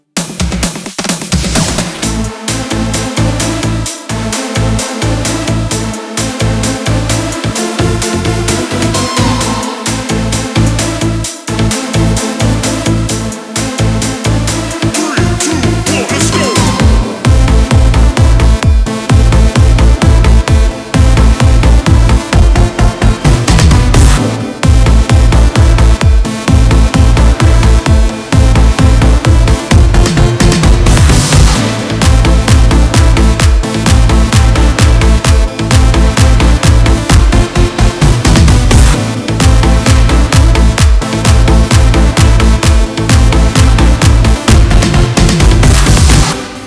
אינטרו אני חושב, תודה בכול מקרא!